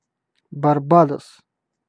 Ääntäminen
Vaihtoehtoiset kirjoitusmuodot (vanhahtava) Barbadoes Ääntäminen US UK : IPA : /bɑː(ɹ)ˈbeɪ.dɒs/ US : IPA : /bɑɹˈbeɪ.doʊs/ Lyhenteet ja supistumat (laki) Barb.